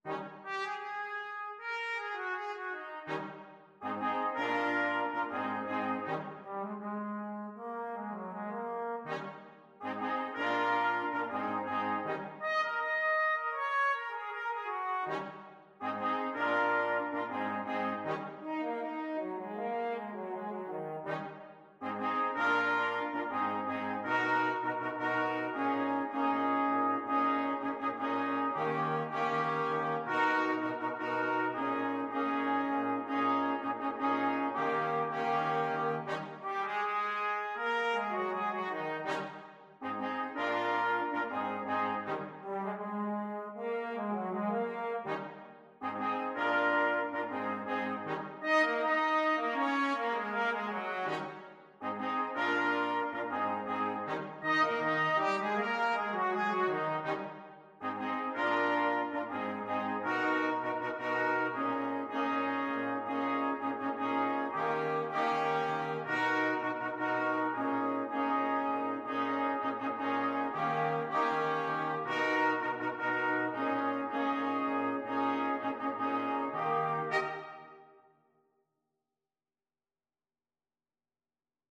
Trumpet 1Trumpet 2French HornTrombone
4/4 (View more 4/4 Music)
Pop (View more Pop Brass Quartet Music)